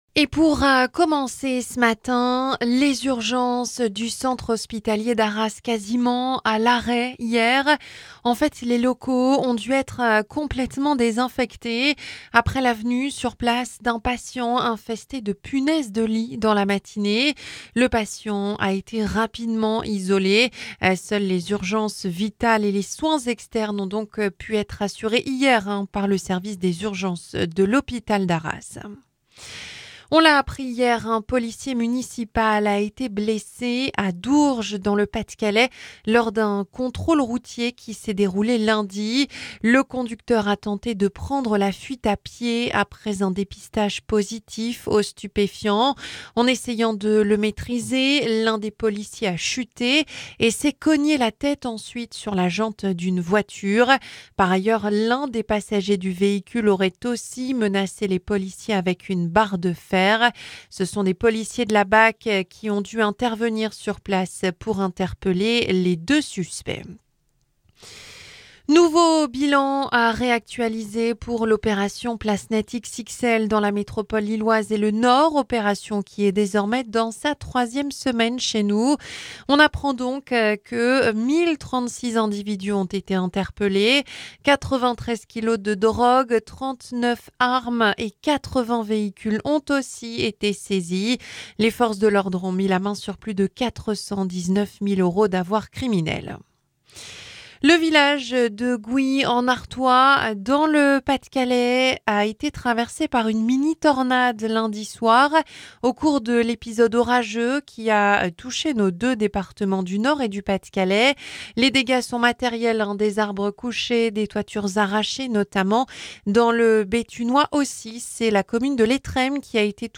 Journal 9h - Le fonctionnement des urgences de l'hôpital d'Arras perturbé hier, par des punaises de lit